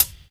Track 14 - Hi-Hat OS 01.wav